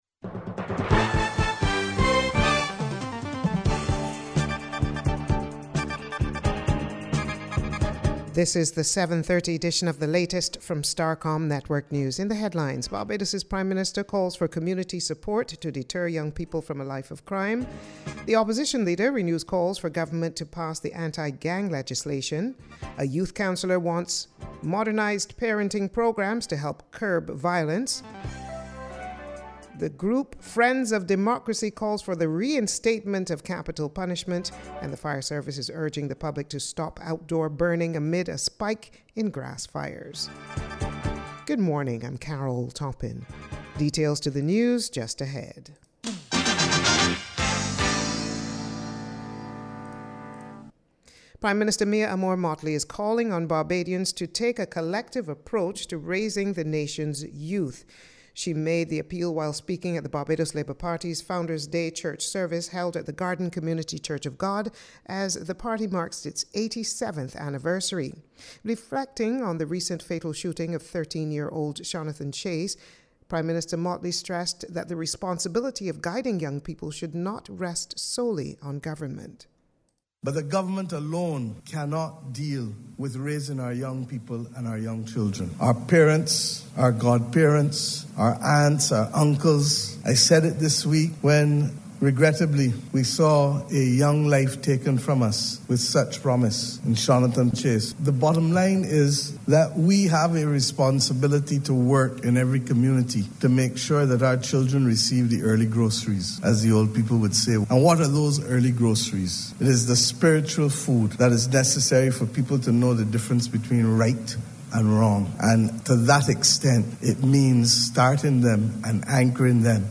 She was speaking at the ceremony to mark the 46th anniversary of the bombing of Cubana Airlines Flight 455 which crashed off Barbados.